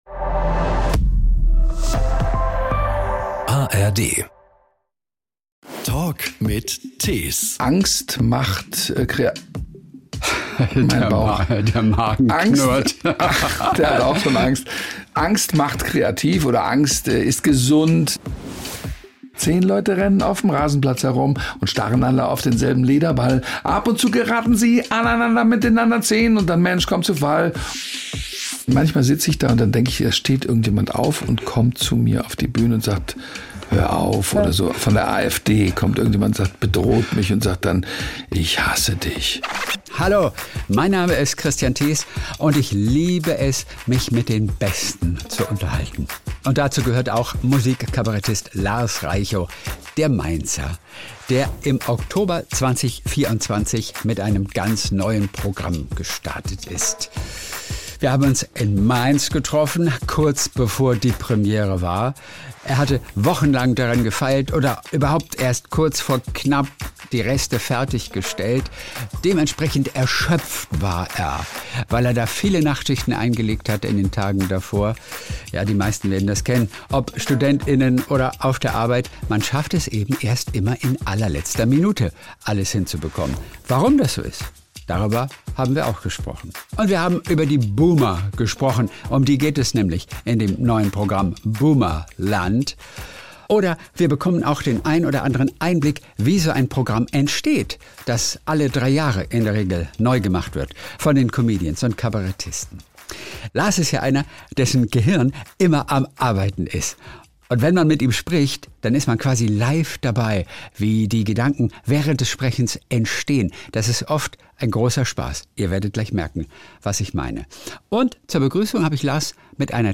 Lars rappt zum Thema Fußball, und die beiden sprechen auch über unvorhergesehene Dinge, die auf der Bühne passieren.